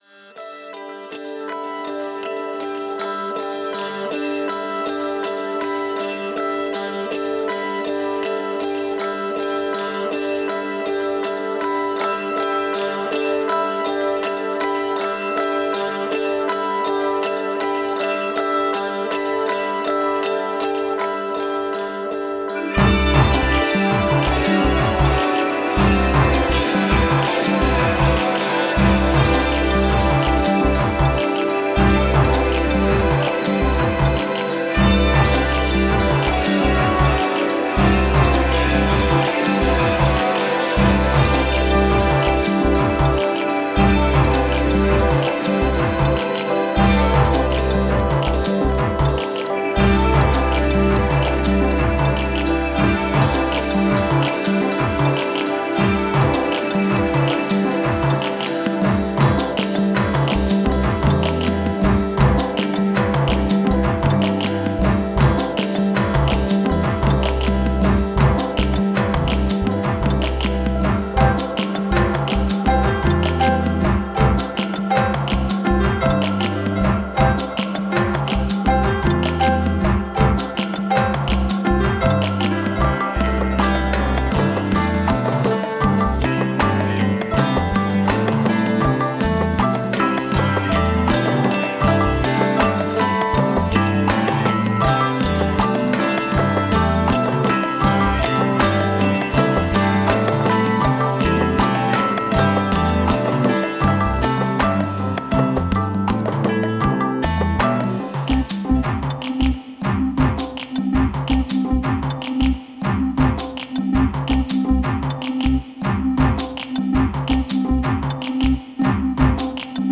Ambient Trance Prog aus Offenbach aM